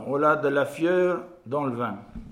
Localisation Sainte-Christine
Catégorie Locution